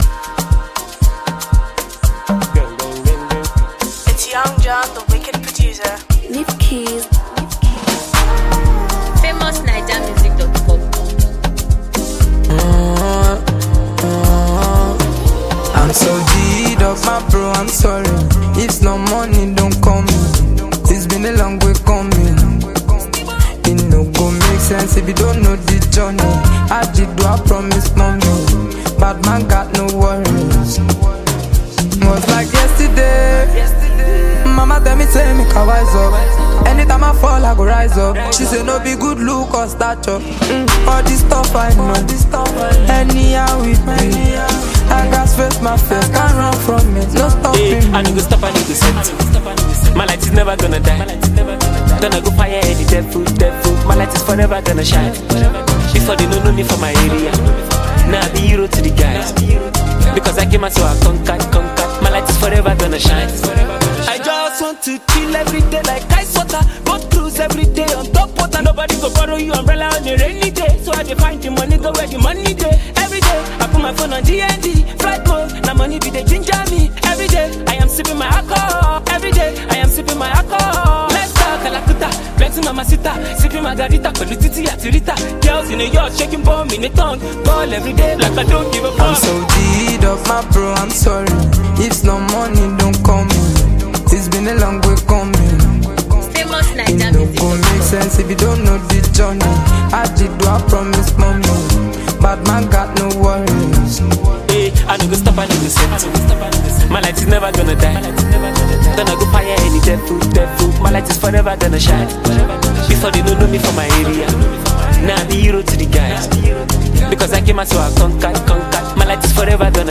a Nigerian skilled afrobeat vocalist